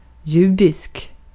Ääntäminen
Ääntäminen Tuntematon aksentti: IPA: /ˈjʉːd.ɪsk/ Haettu sana löytyi näillä lähdekielillä: ruotsi Käännös Adjektiivit 1.